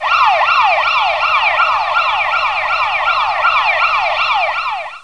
1 channel
siren.mp3